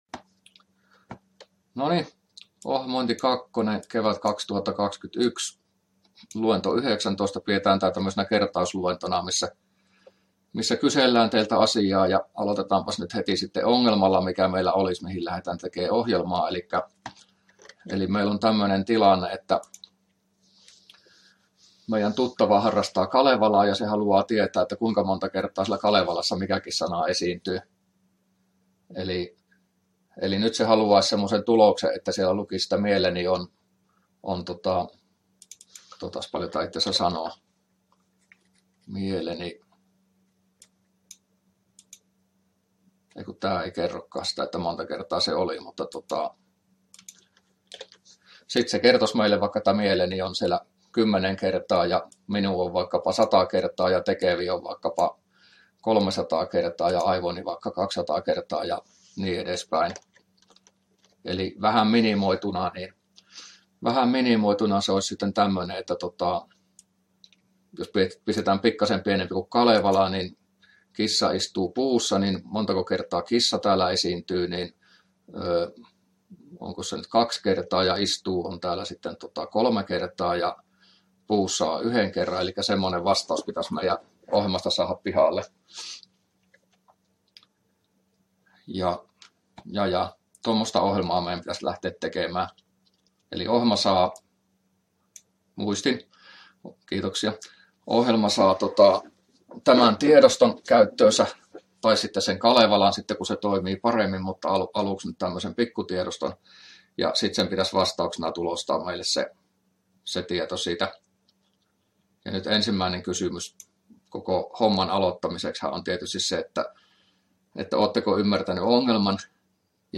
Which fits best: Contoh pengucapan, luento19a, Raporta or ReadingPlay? luento19a